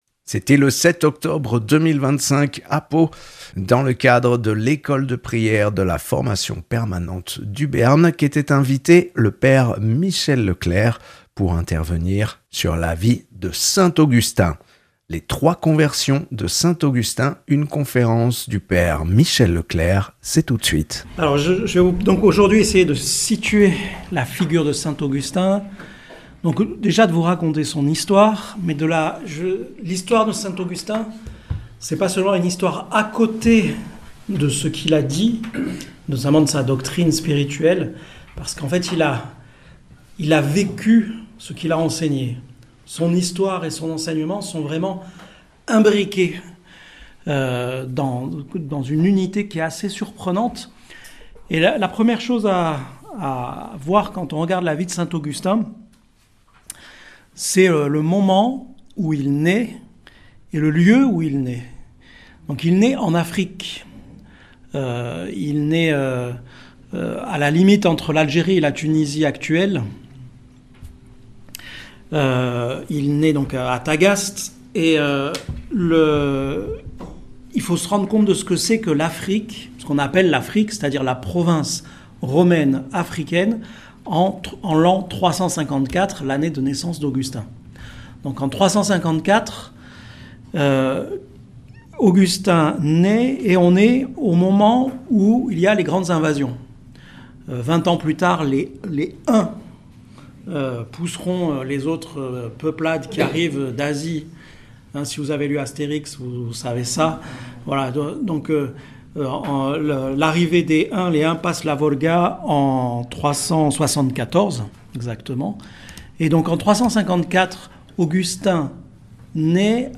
Religion
(enregistré à Pau le 7 octobre 2025)